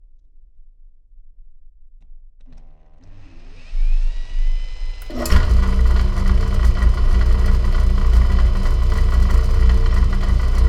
drill-start.wav